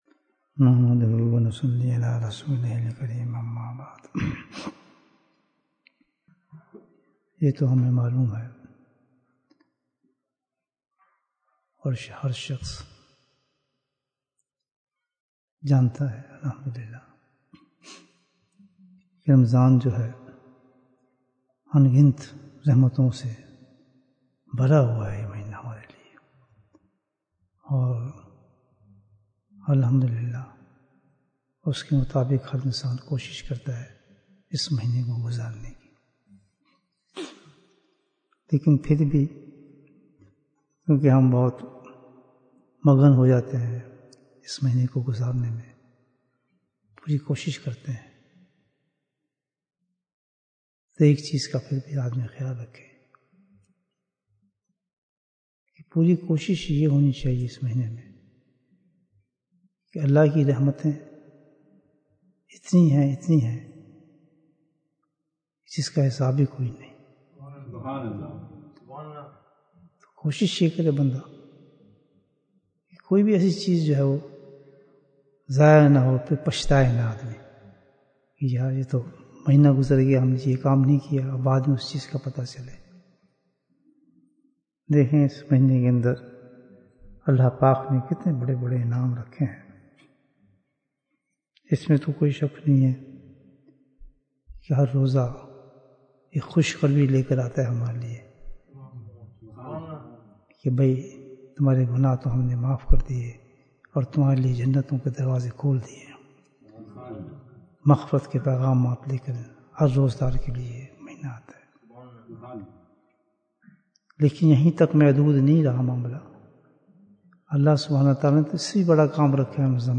Wednesday’s Bayan after Asr